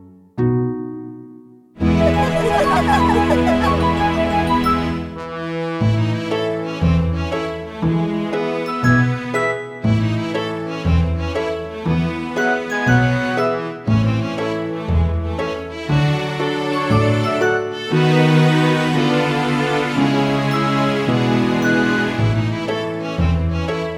no Backing Vocals Soundtracks 3:17 Buy £1.50